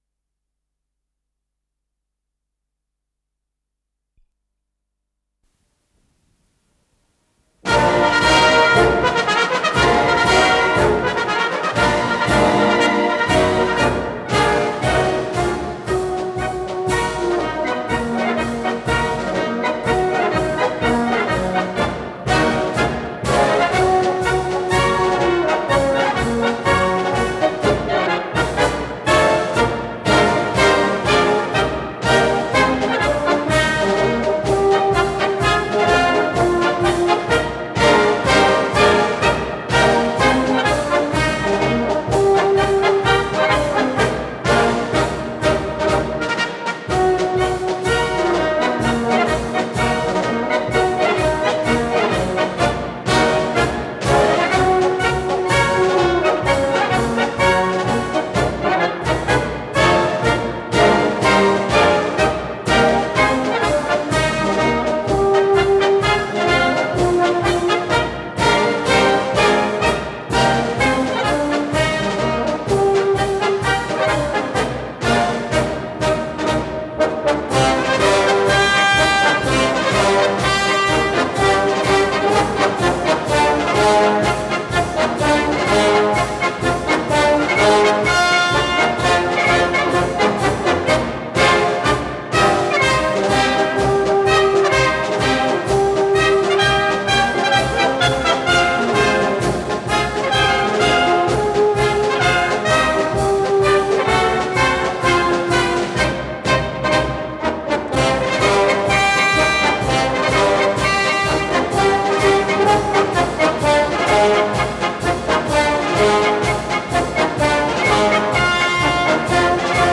с профессиональной магнитной ленты
ми бемоль минор - фа минор
ВариантДубль моно